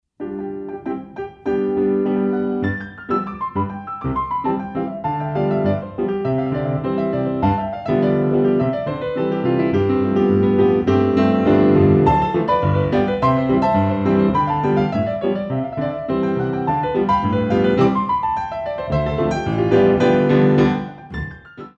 Pas de pointes